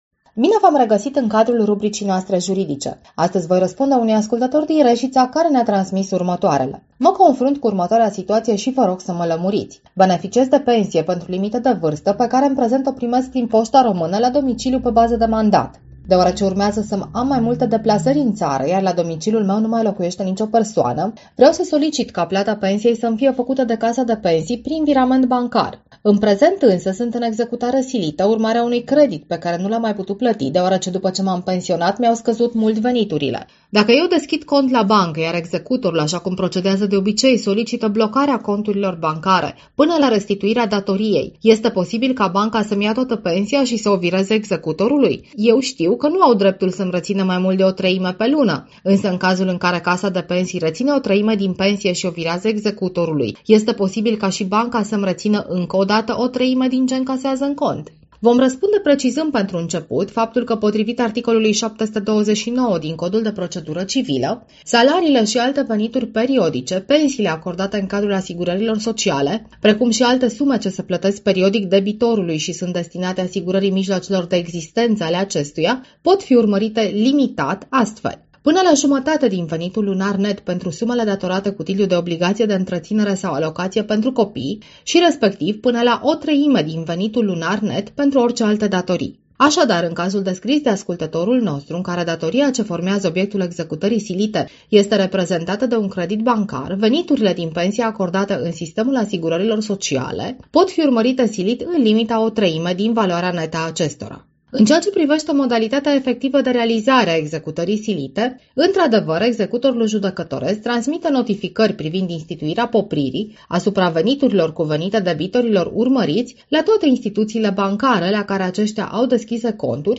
Rubrica juridică este difuzată  pe frecvențele noastre în fiecare zi de luni.